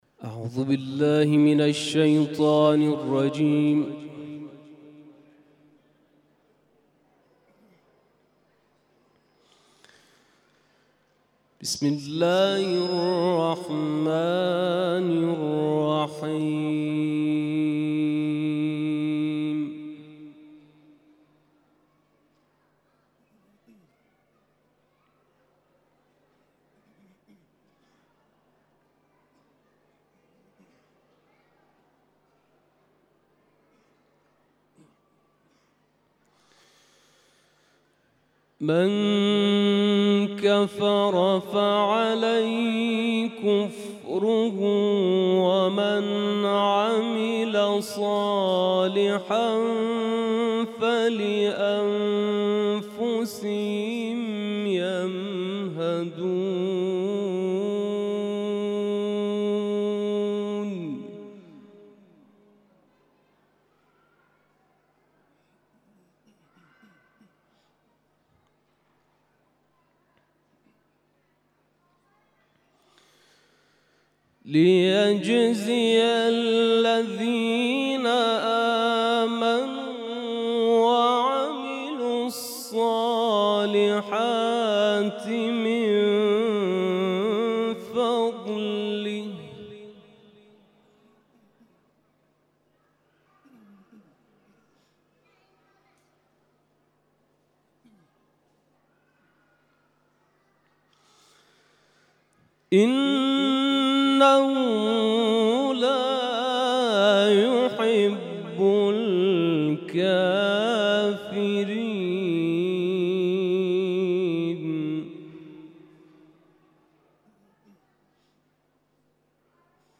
تلاوت قران کریم